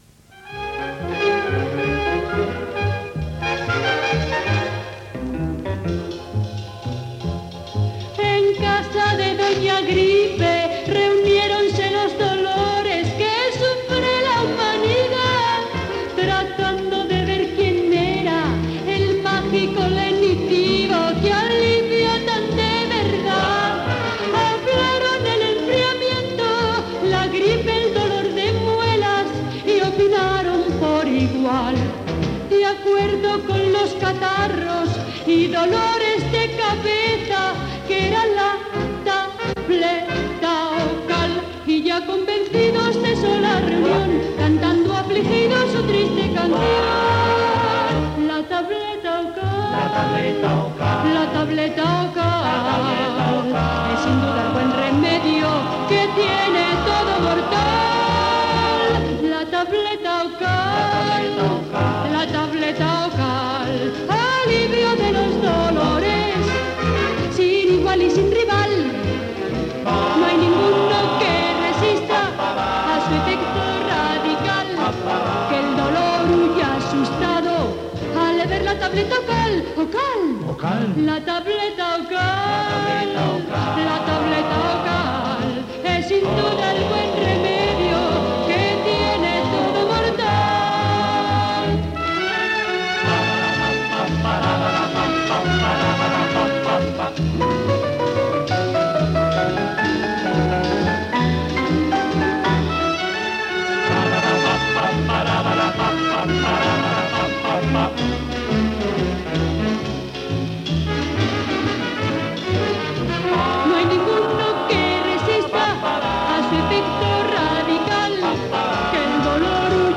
Cançó publicitària
amb acompanyaments d'orquestra.